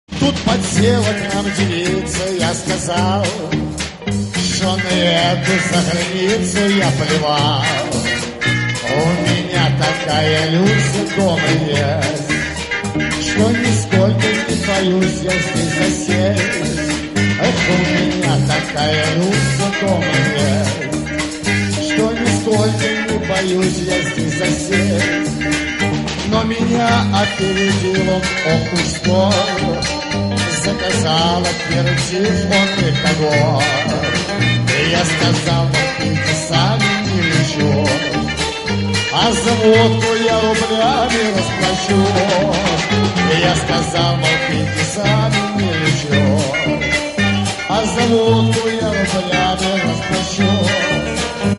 • Качество: 64, Stereo
блатные